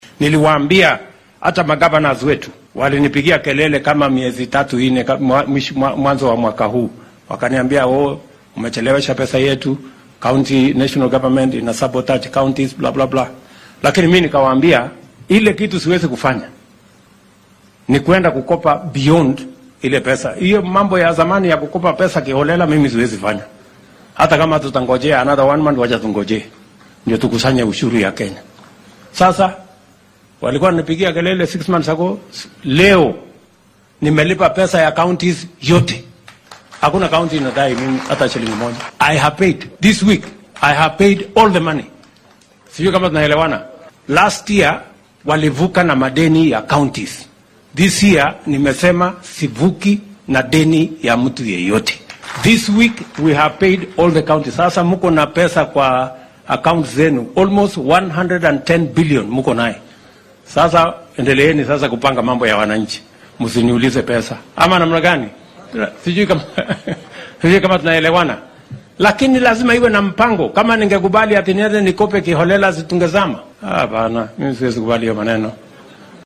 Ruto ayaa xilli uu ku sugnaa Kajiado hoosta ka xarriiqay in uunan jirin ismaamul hal shilin ku leh dowladda sare.